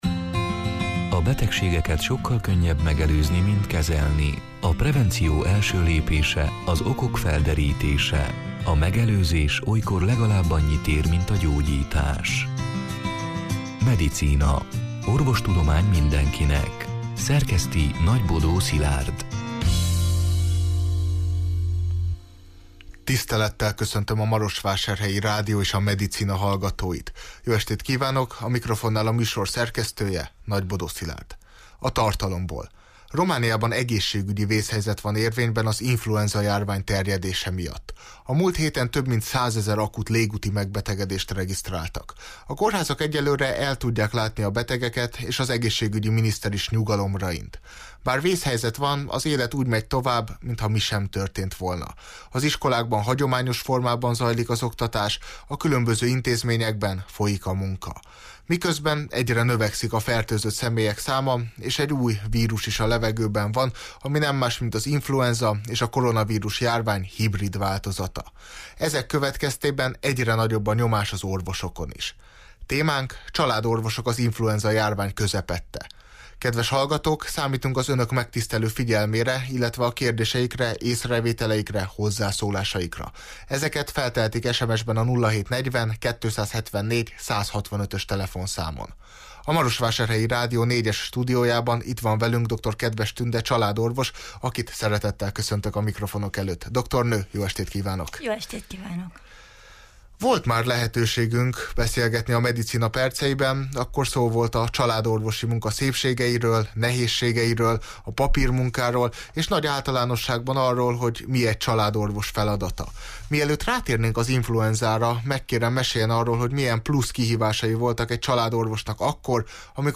A Marosvásárhelyi Rádió (elhangzott: 2023. január 18-án, szerdán este nyolc órától élőben) Medicina c. műsorának hanganyaga: Romániában egészségügyi vészhelyzet van érvényben az influenzajárvány terjedése miatt.